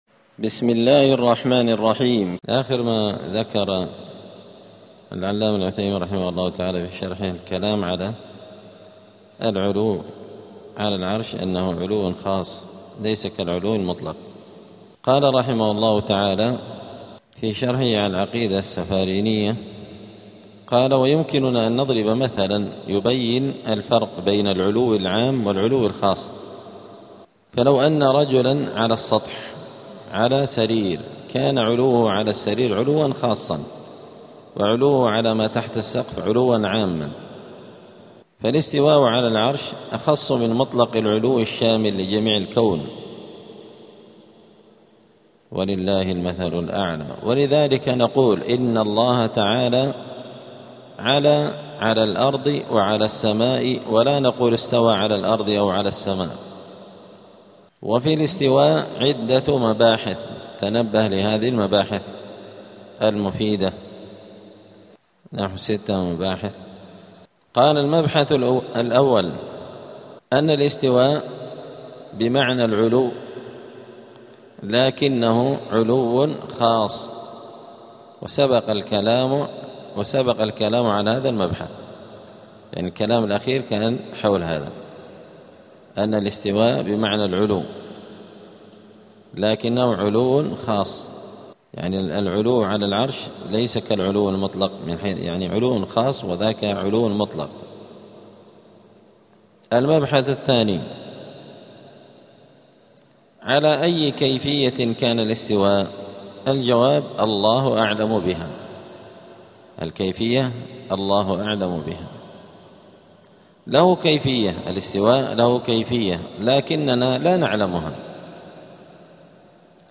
دار الحديث السلفية بمسجد الفرقان قشن المهرة اليمن
42الدرس-الثاني-والأربعون-من-شرح-العقيدة-السفارينية.mp3